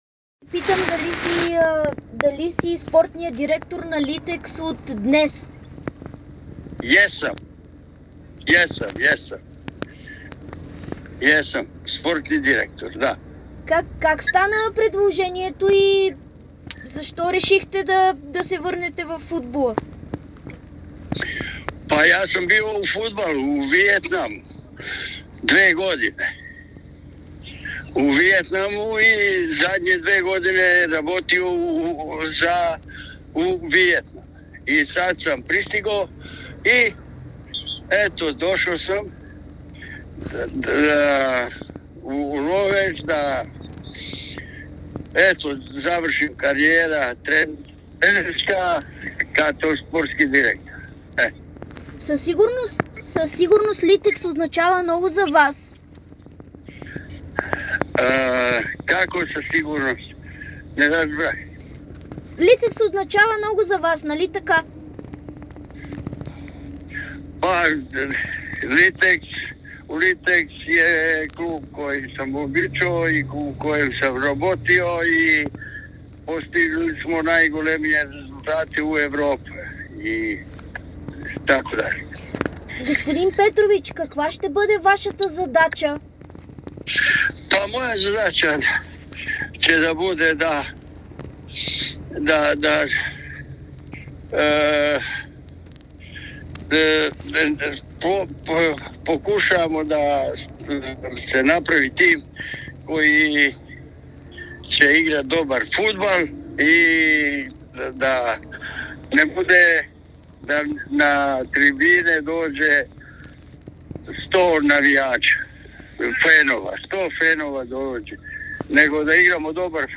Новият спортен директор на Литекс Ловеч – Люпко Петрович, говори специално пред dsport и потвърди информацията. Той разкри, че неговото желание е да си завърши футболната кариера в България и в частност – Литекс.